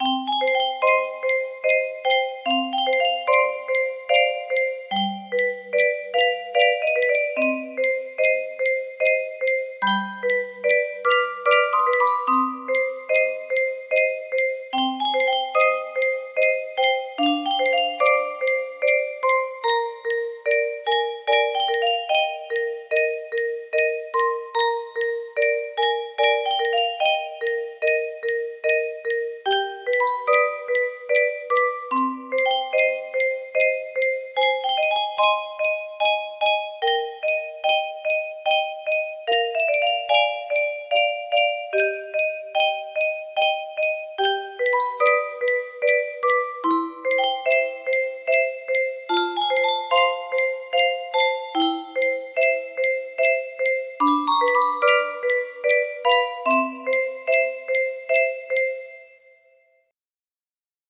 Franz Schubert - Schwanengesang: Staedchen music box melody
Grand Illusions 30 (F scale)